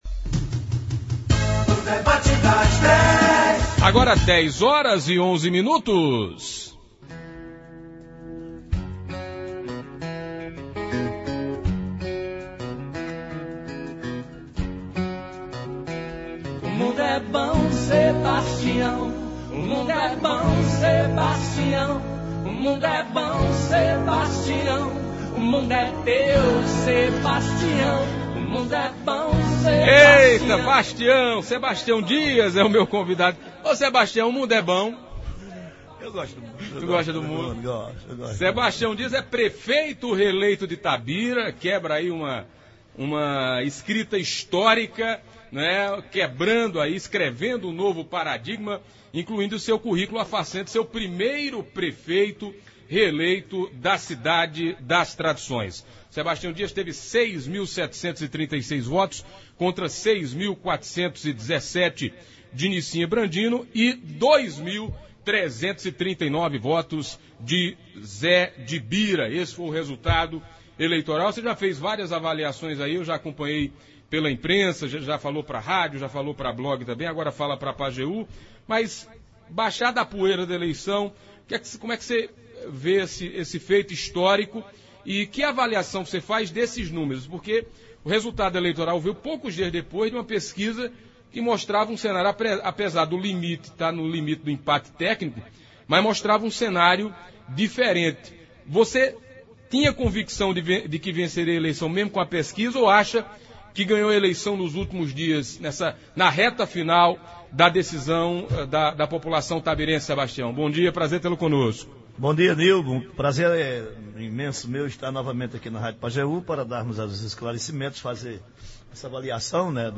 Nesta segunda-feira (24), o prefeito reeleito de Tabira Sebastião Dias falou ao Debate das Dez do Programa Manhã Total, da Rádio Pajeú. O prefeito destacou que apesar da rejeição de sua gestão, que ele disse ser menor que o aferido em pesquisas, a população entendeu que devia dar a ele mais uma chance.